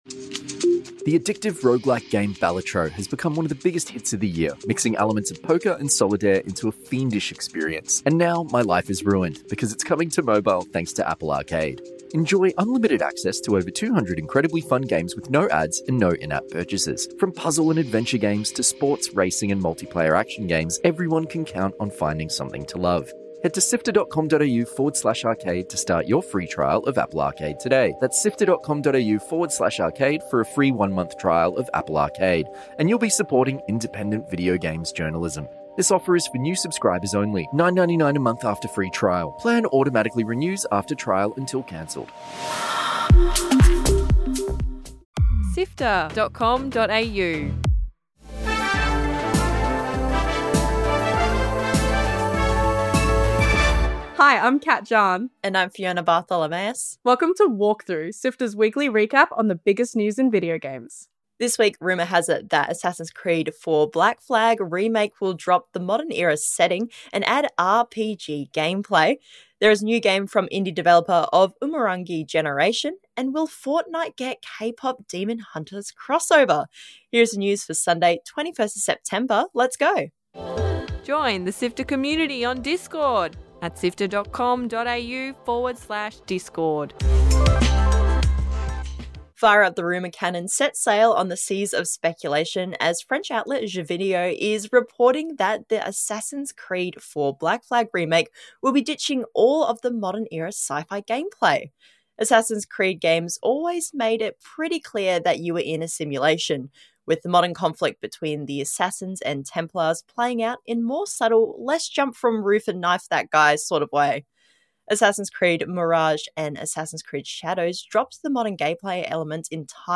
Catch up on the gaming news with SIFTER's weekly news show in around fifteen minutes or less. All the biggest news, new release dates and explainers of the biggest video game stories every Sunday.